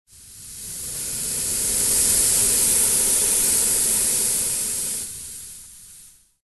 Звуки разбивания машины
Звук перегретого двигателя при неисправности